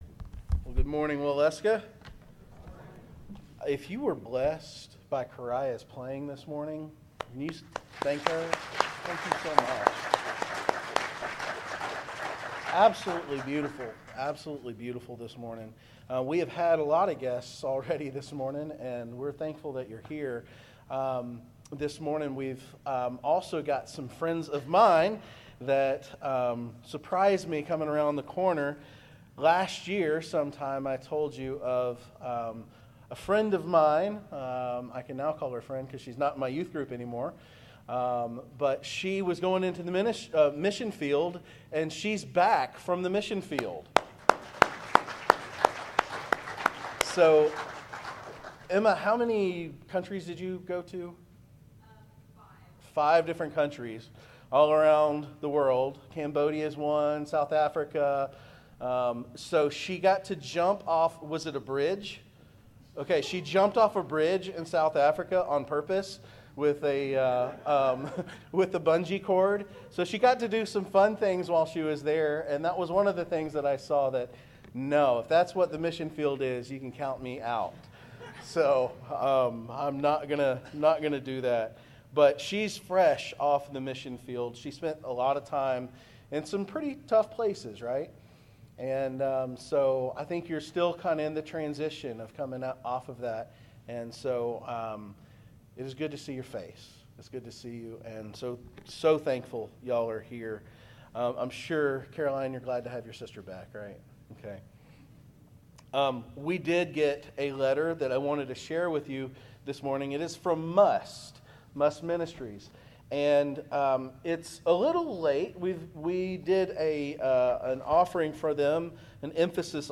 Sermons | Waleska First Baptist Church
Guest Speaker